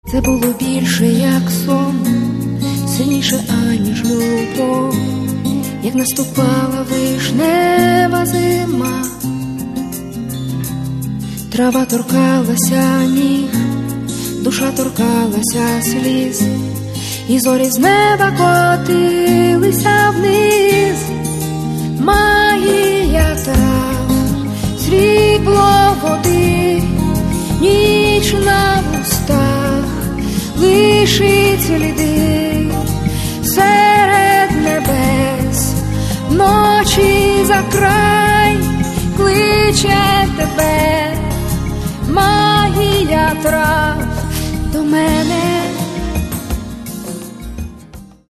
Каталог -> Поп (Легкая) -> Сборники